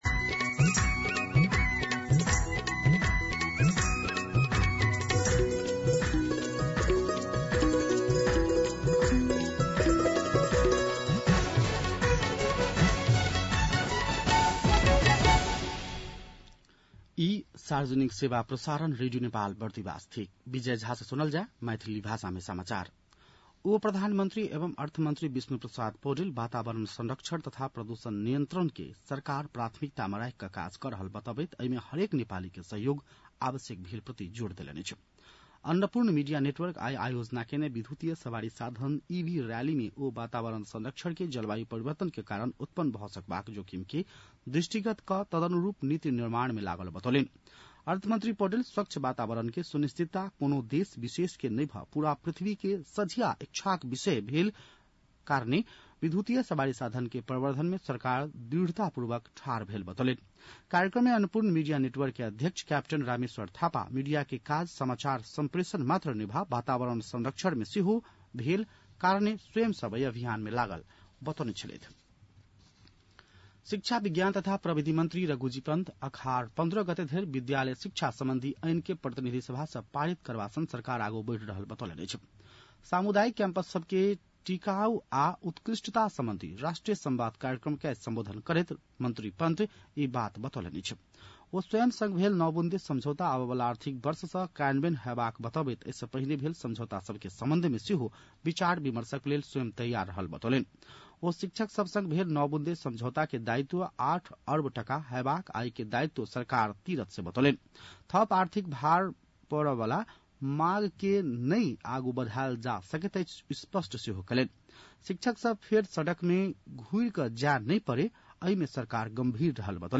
मैथिली भाषामा समाचार : ३१ जेठ , २०८२
6-pm-maithali-news-1-1.mp3